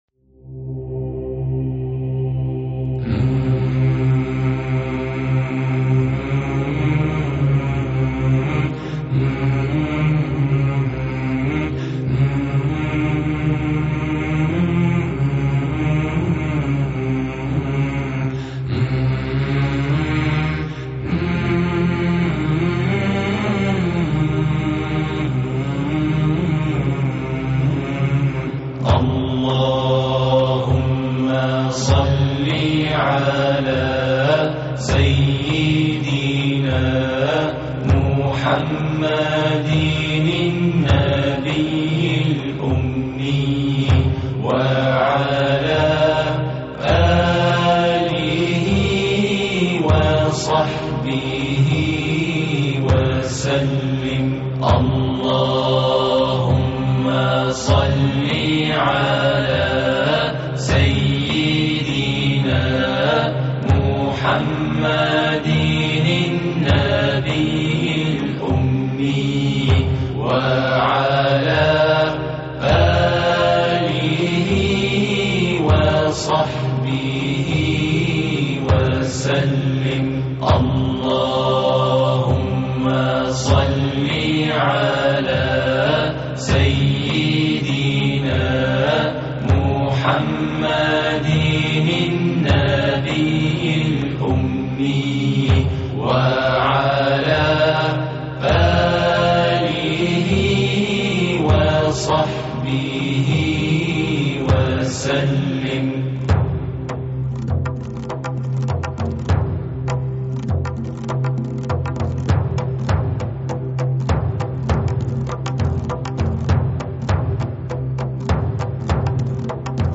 " Naat MP3